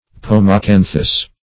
pomacanthus.mp3